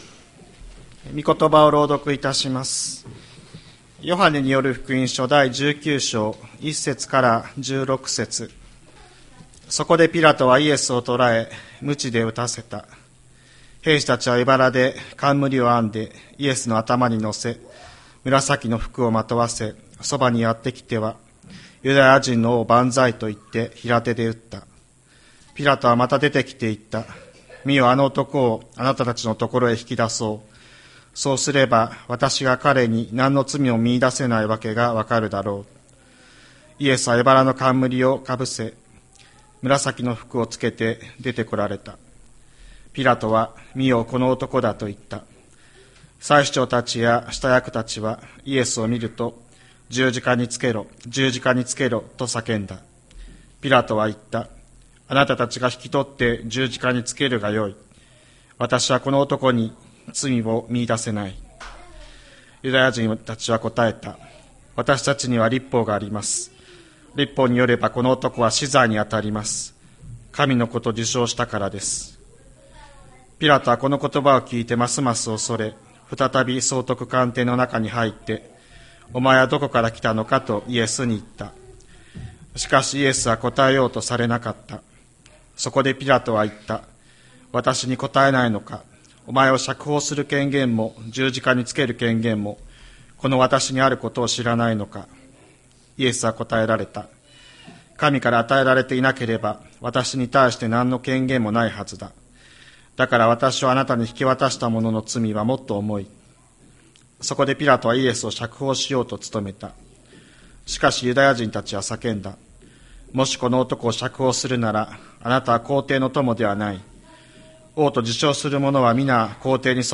2024年03月10日朝の礼拝「わたしたちのための苦しみ」吹田市千里山のキリスト教会
千里山教会 2024年03月10日の礼拝メッセージ。